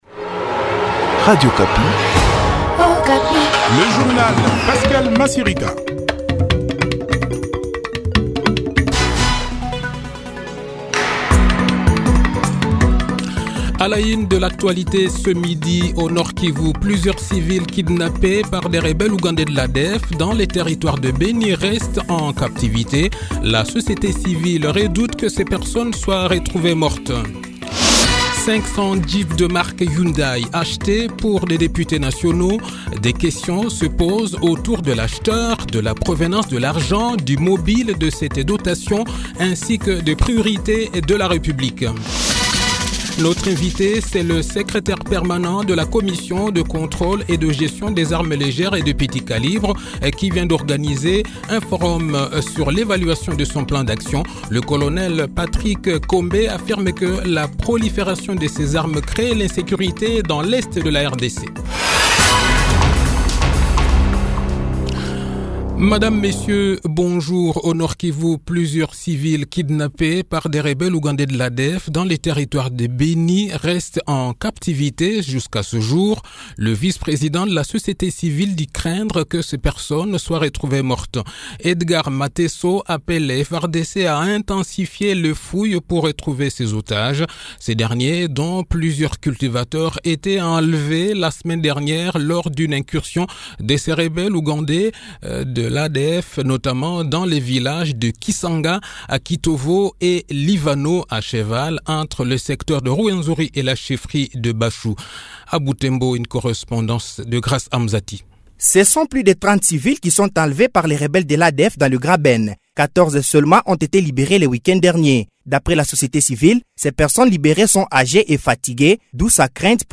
Le journal de 12 h, 21 Juin 2021